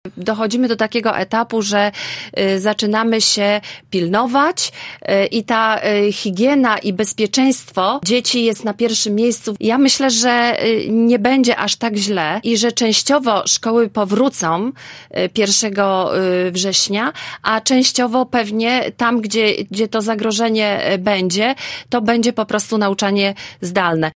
Mówiła o tym w „Rozmowie Punkt 9” lubuska wicekurator oświaty, Katarzyna Pernal-Wyderkiewicz: